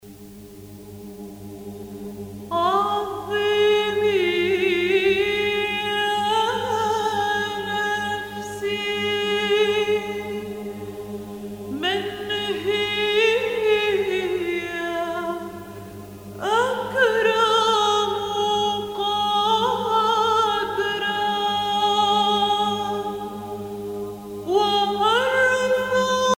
circonstance : dévotion, religion
Pièce musicale éditée